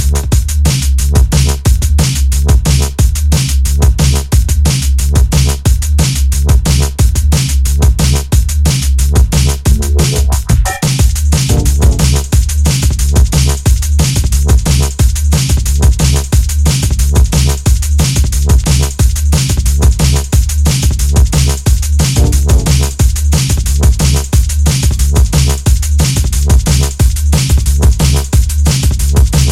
TOP > Jump Up / Drum Step